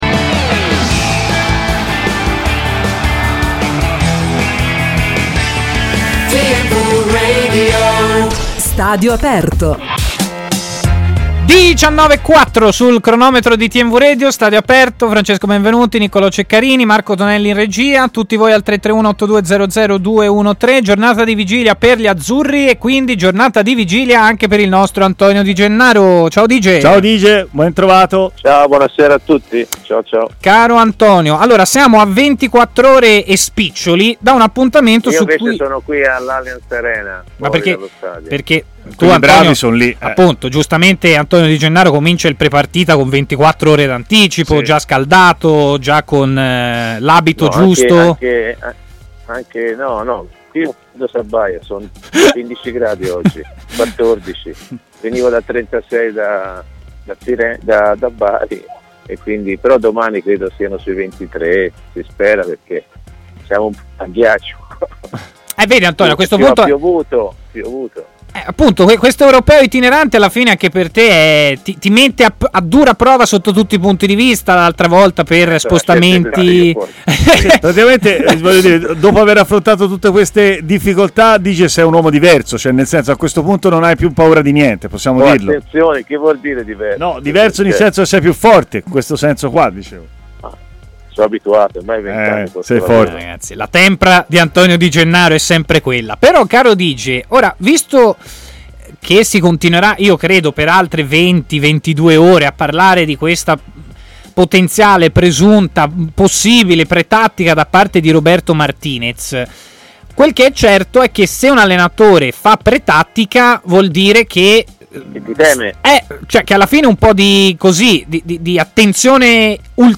L'ex centrocampista Antonio Di Gennaro, commentatore tv e opinionista TMW Radio, ha parlato in diretta a Stadio Aperto